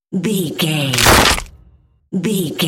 Dramatic hit bloody bone
Sound Effects
heavy
intense
dark
aggressive
hits